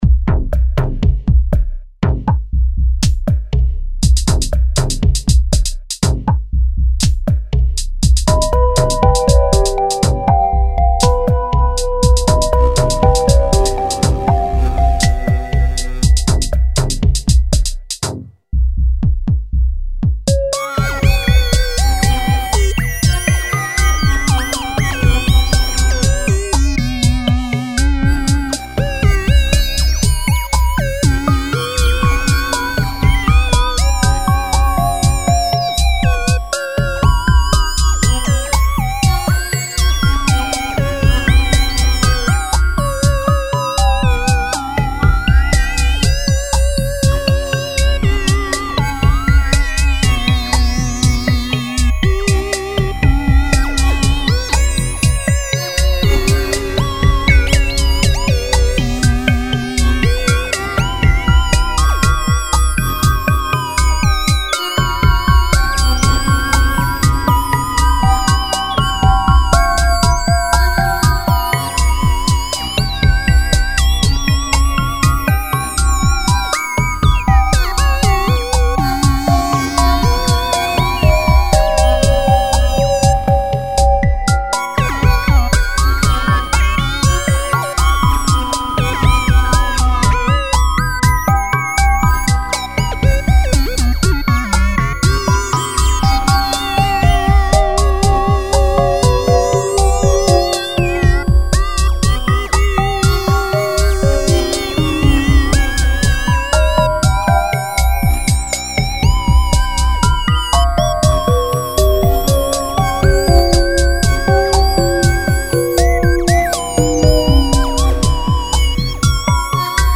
Categorised in: atmospheric, rhythmic